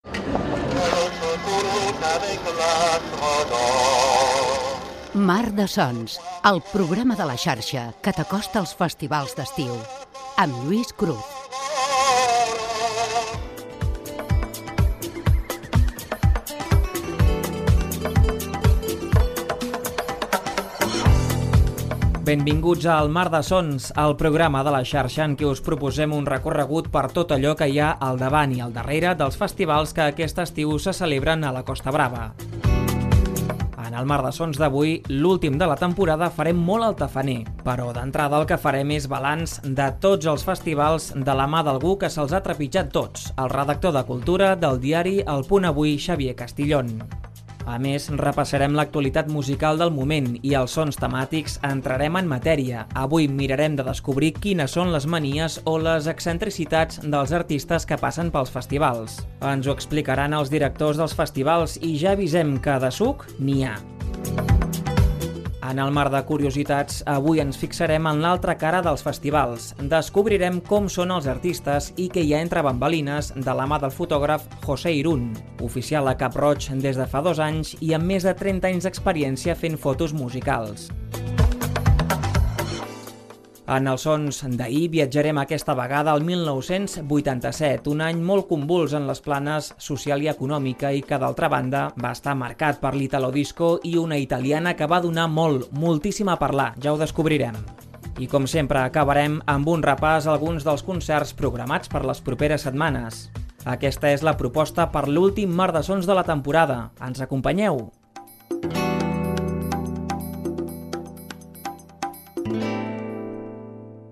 Indicatiu del programa, presentació del programa que dóna informació sobre els festivals d'estiu a la Costa Brava